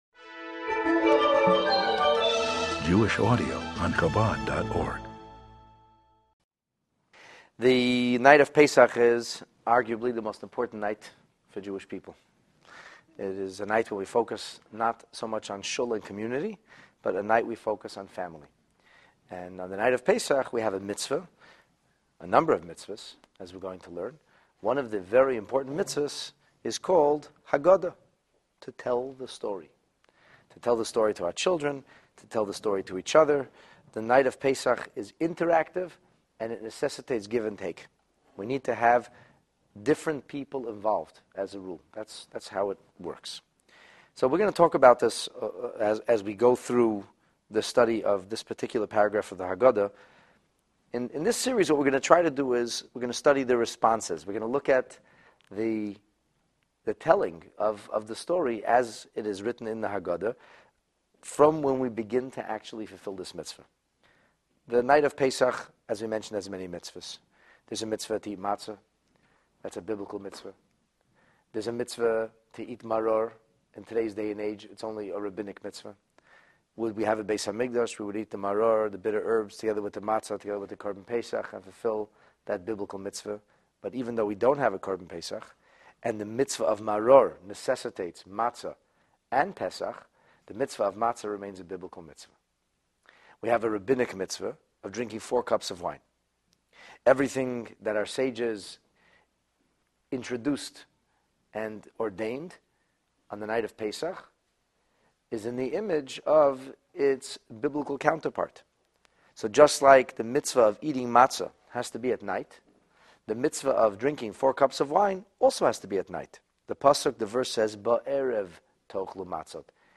If G-d would not have taken us out, would we really still be slaves in Egypt? This class begins from right after the child asks the Four Questions, and covers the paragraph “Avadim Hayinu” (We were slaves in Egypt).